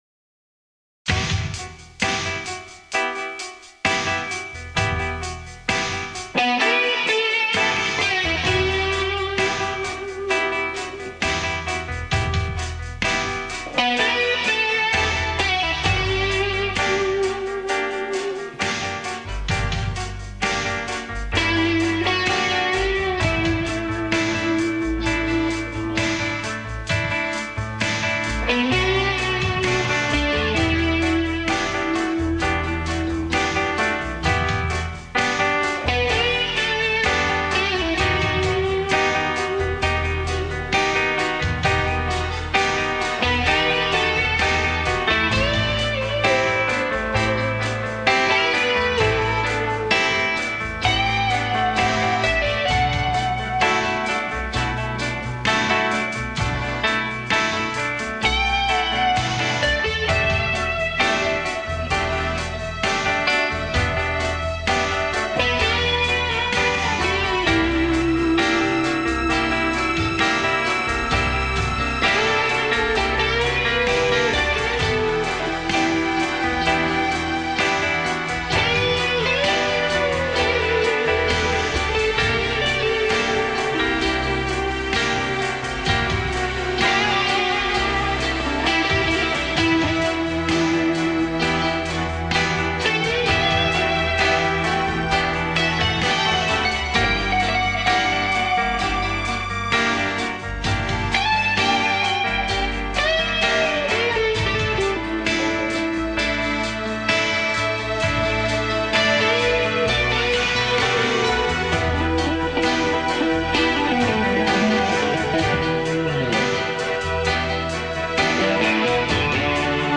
Music Rock Soundtrack
موسیقی راک
اهنگ بی کلام